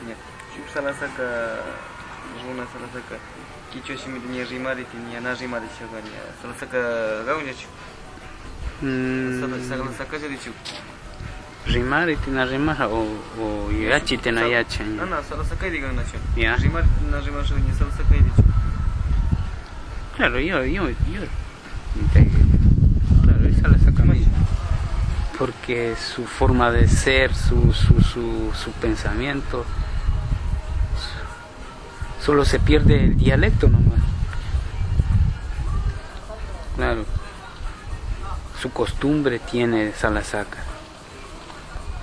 Entrevistas - San Cristóbal